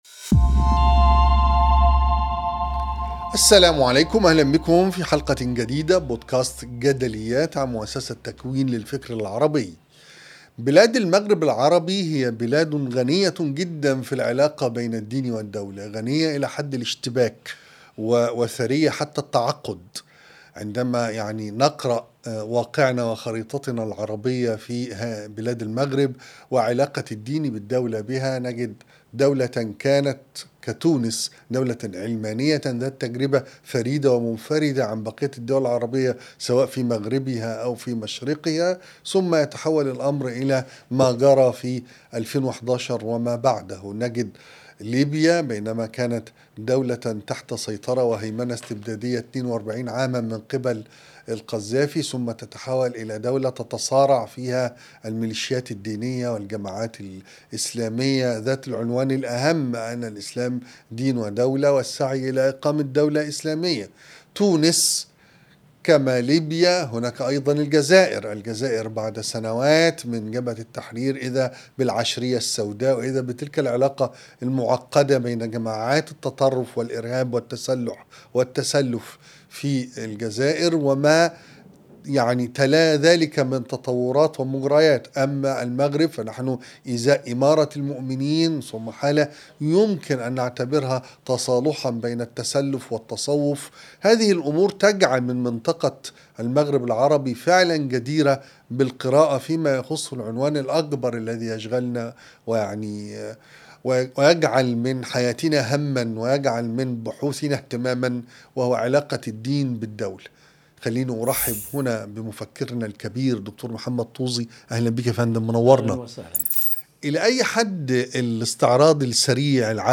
يستضيف الإعلامي إبراهيم عيسى